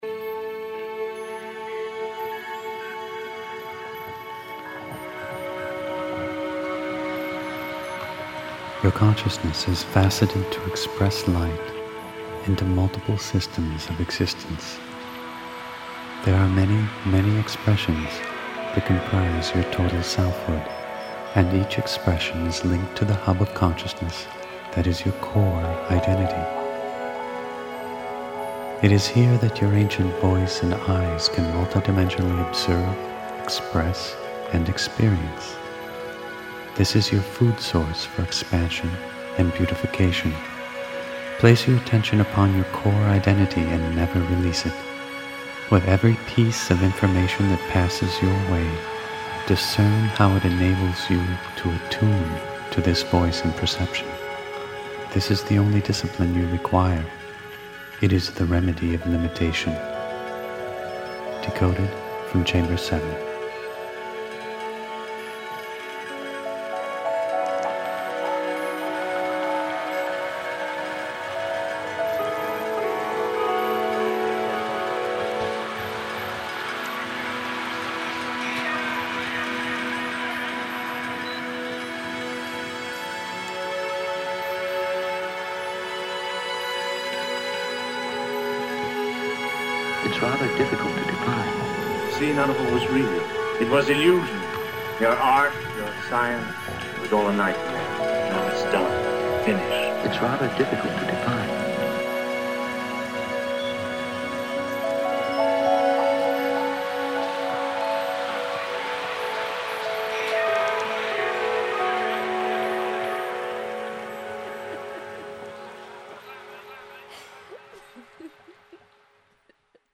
Filosofia Inediti – Audiolettura